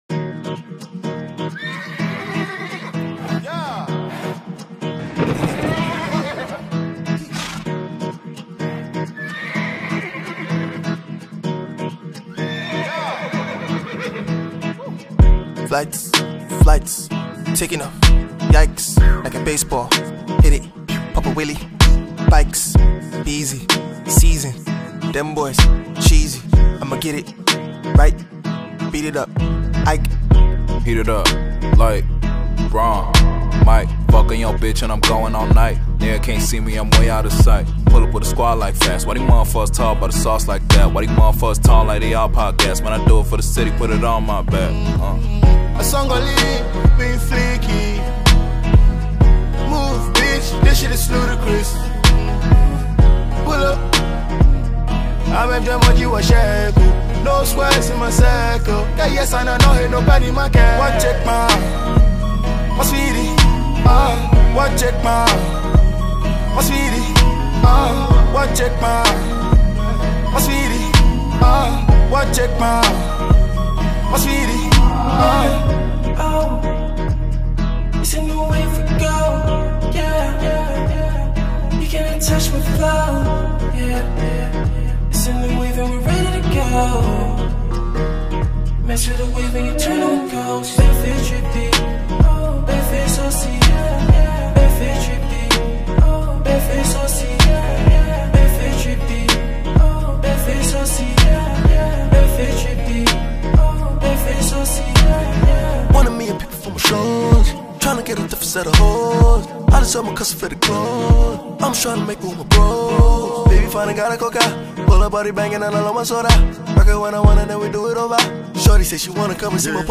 Award-winning Ghanaian Afrobeat/Afropop singer